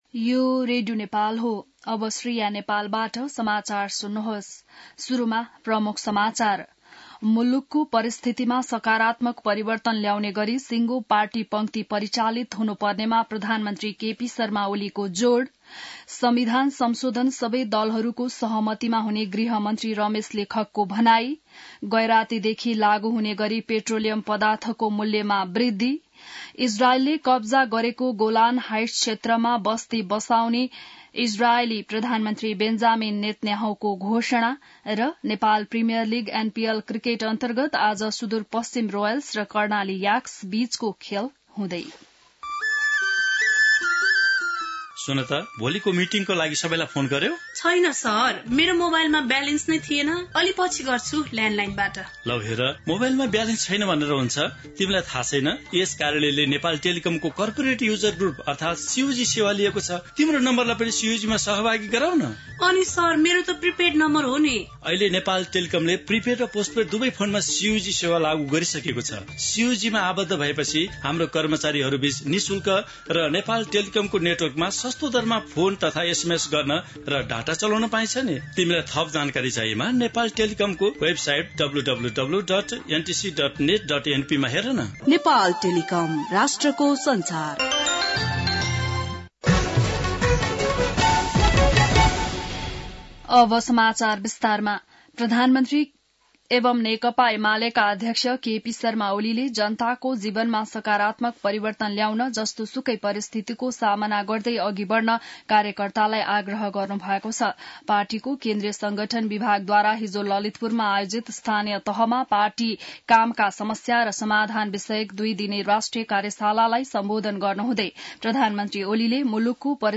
An online outlet of Nepal's national radio broadcaster
बिहान ७ बजेको नेपाली समाचार : २ पुष , २०८१